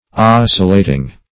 Oscillating \Os"cil*la`ting\ ([o^]s"s[i^]l*l[=a]`t[i^]ng), a.